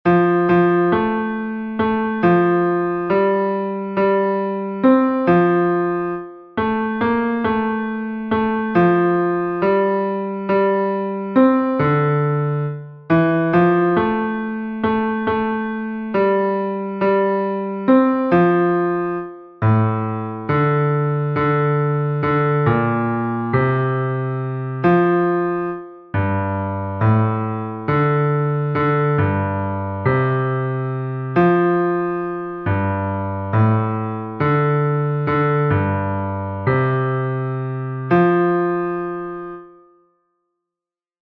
Basse